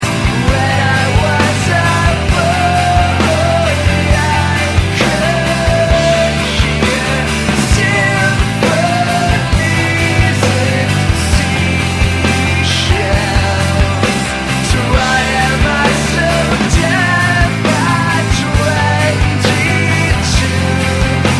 drums
bass guitar